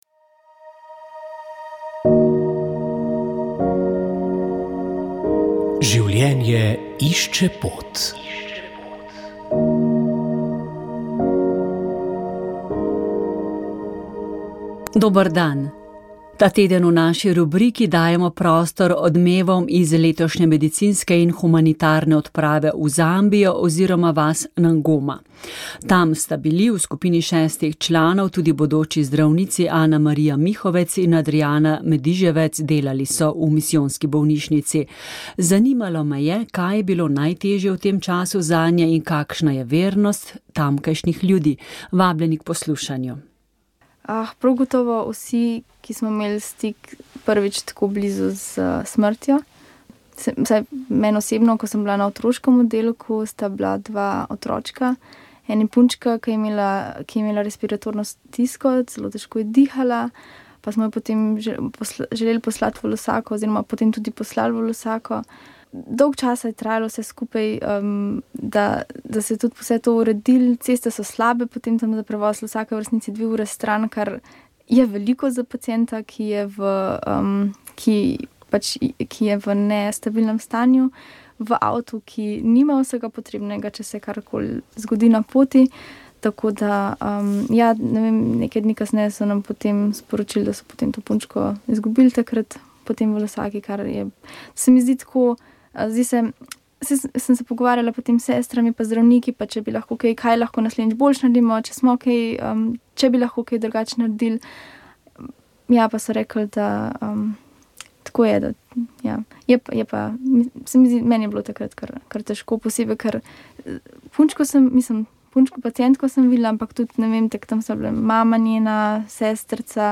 družba pogovor odnosi narava okolje šola izobraževanje